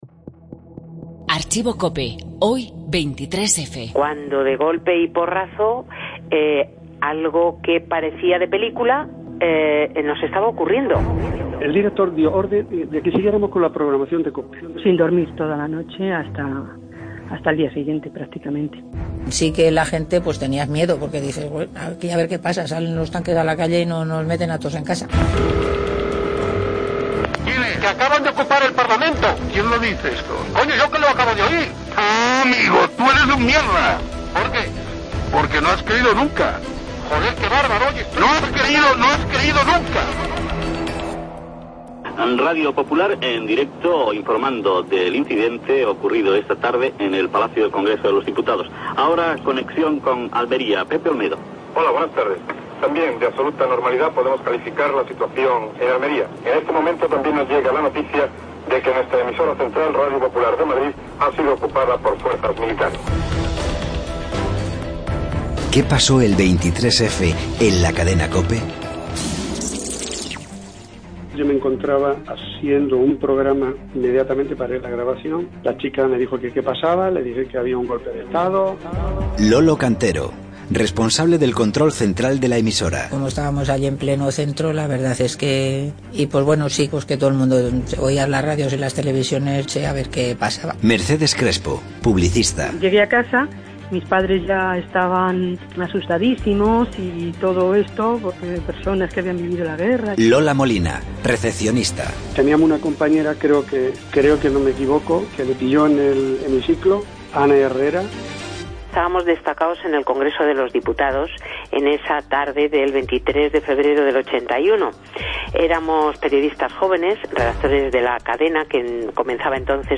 Se cumplen 35 años del intento de golpe de Estado y COPE desvela lo que ocurrió aquel día en la emisora con los testimonios de los periodistas que cubrieron el acontecimiento.